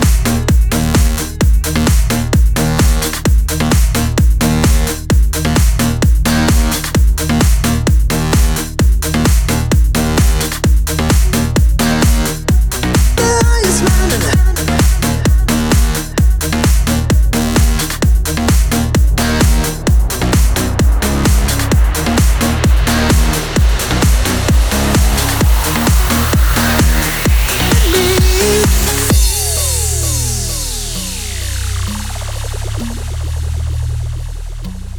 Жанр: Танцевальные / Электроника
Electronic, Dance